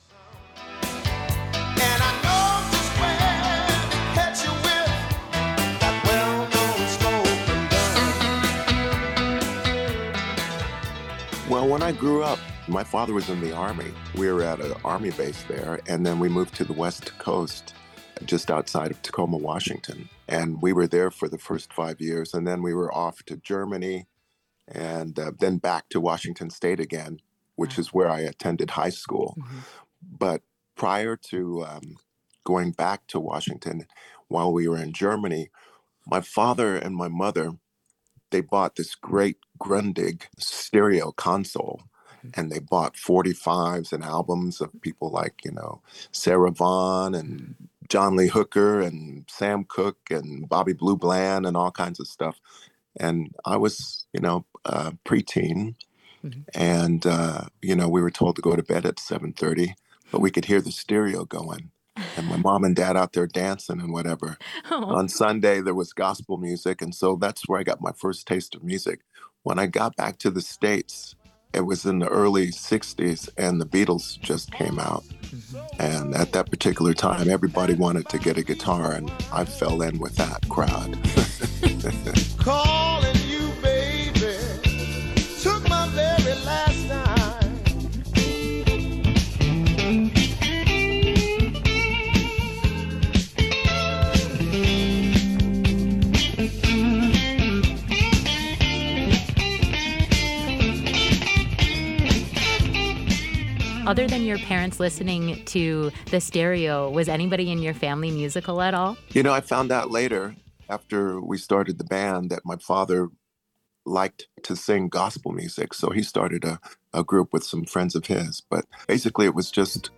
This interview has been edited for clarity and conciseness.